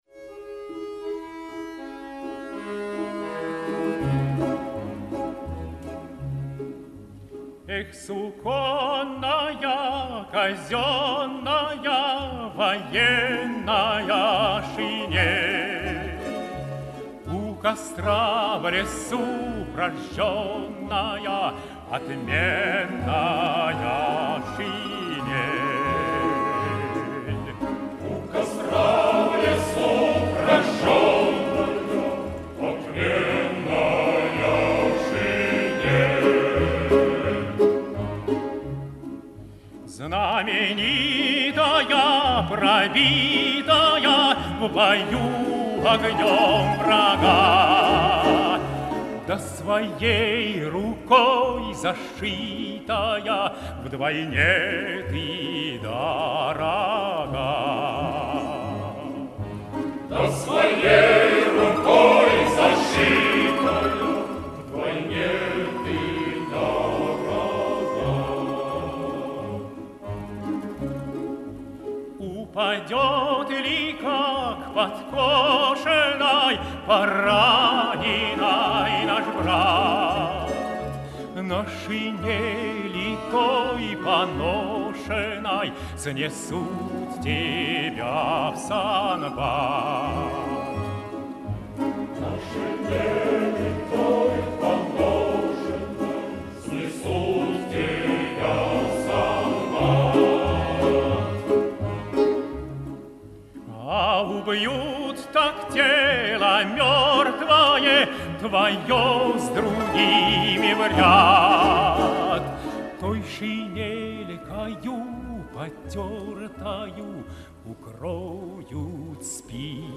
оркестр народных инструментов.